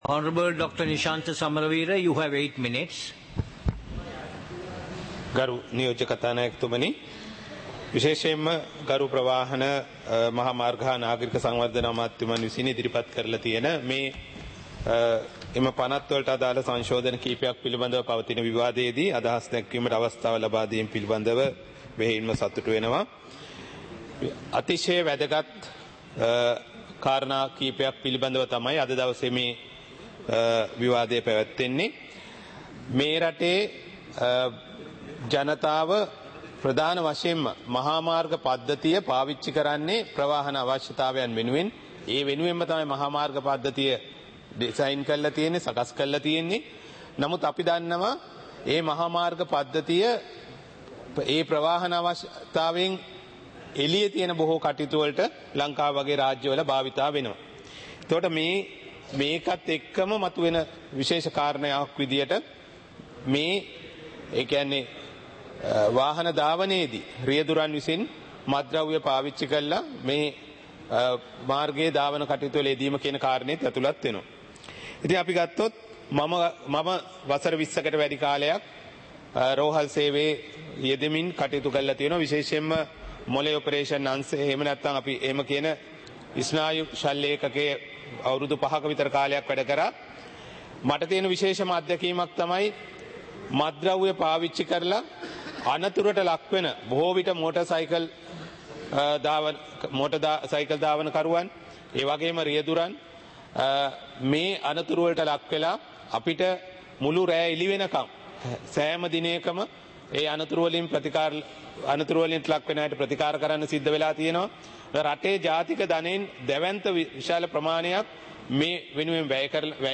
பாராளுமன்ற நடப்பு - பதிவுருத்தப்பட்ட